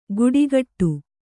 ♪ guḍigaṭṭu